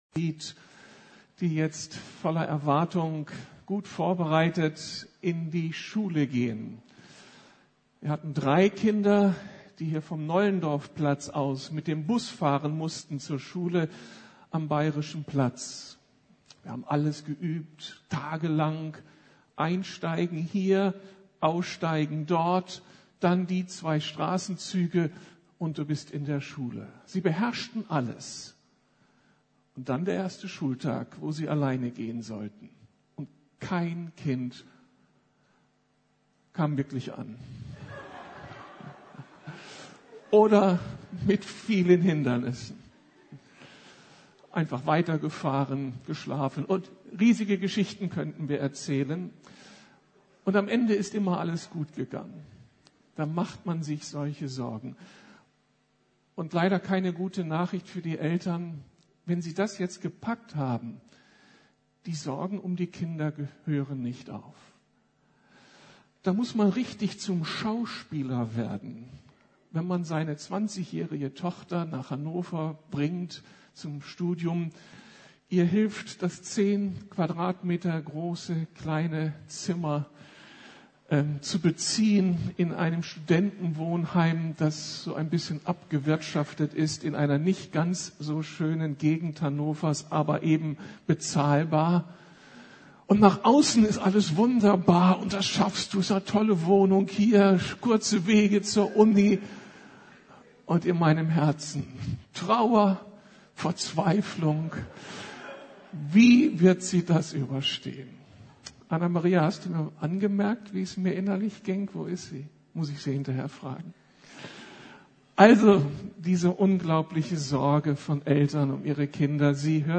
Hilfe, wie schütze ich meine Kinder? ~ Predigten der LUKAS GEMEINDE Podcast